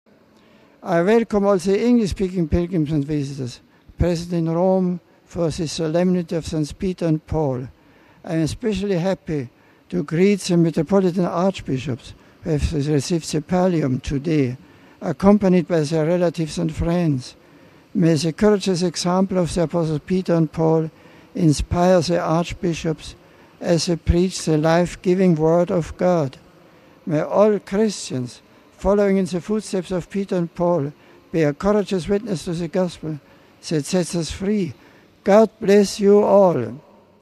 The Mass over, Pope Benedict appeared at the window of studio of his studio to pray the noontime ‘Angelus’ prayer with a holiday crowd gathered in St. Peter’s Square.
After his blessing, Pope Benedict also spoke in several languages.